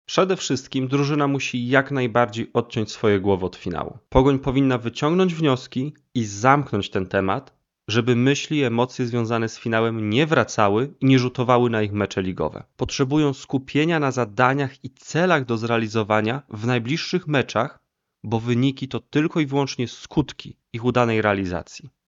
– mówi psycholog sportowy w rozmowie z Twoim Radiem.